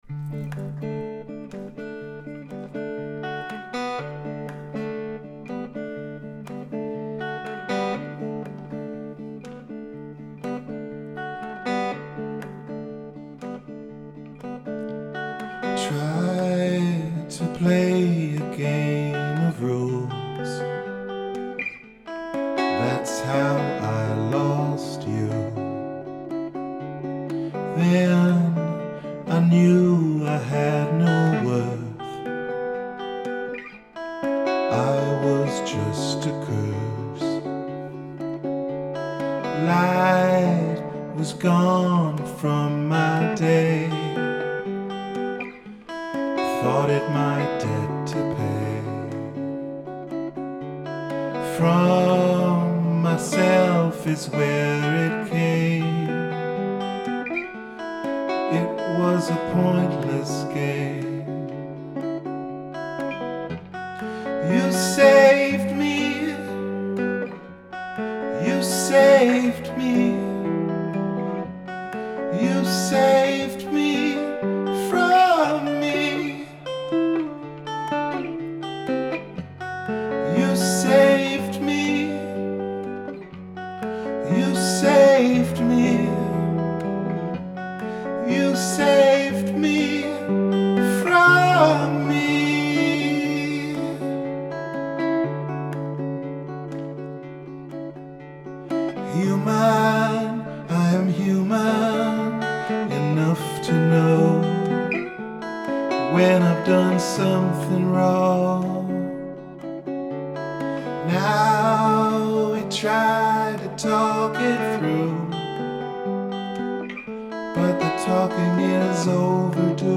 The structure & basic arrangement fixed first time
Rehearsal recordings